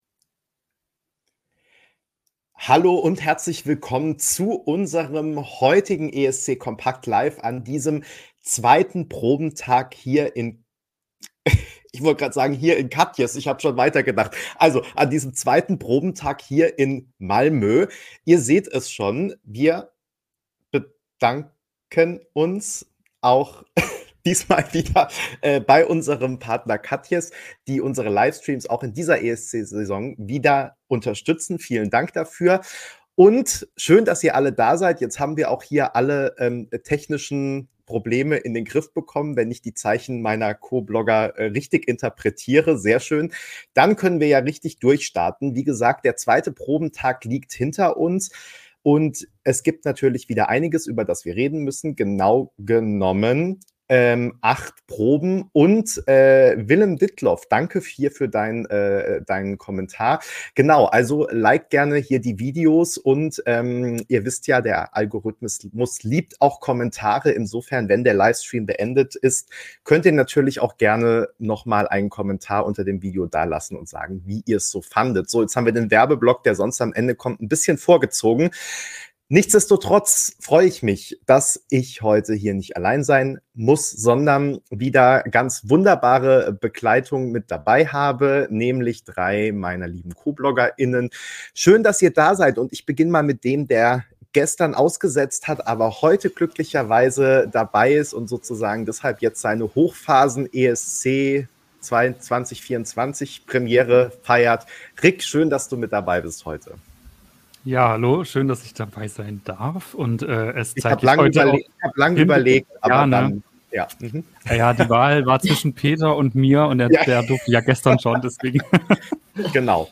ESC kompakt LIVE